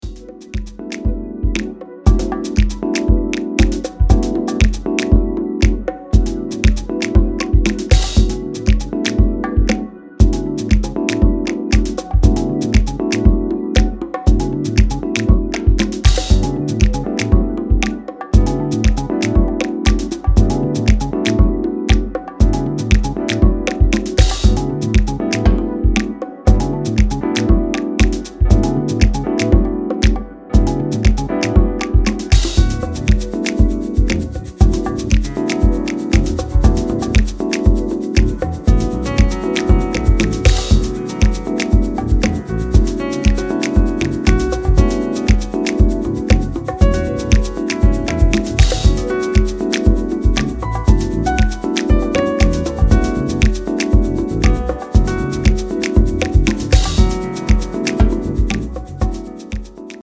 Fantastic broken beat from one of Japan’s rising stars.